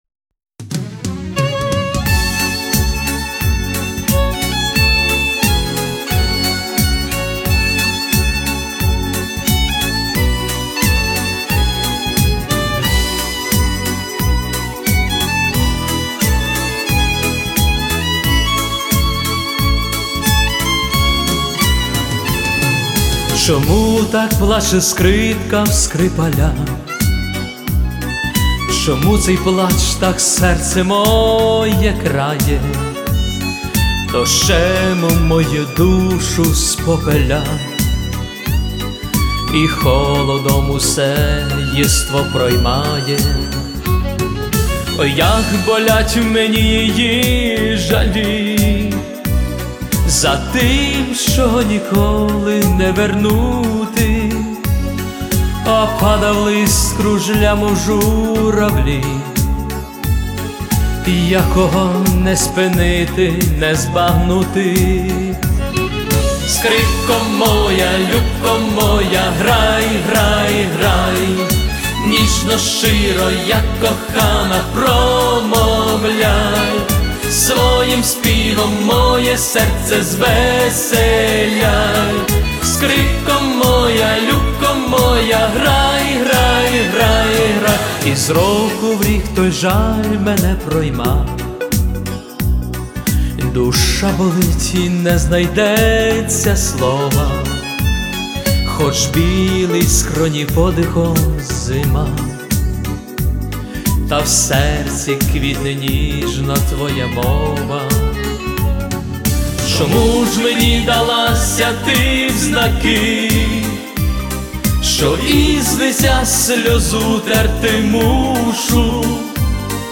Всі мінусовки жанру Ballad
Плюсовий запис